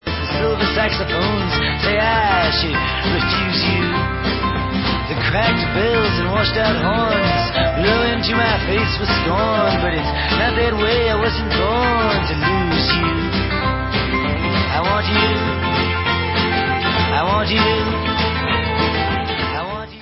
sledovat novinky v kategorii Pop
sledovat novinky v oddělení Písničkáři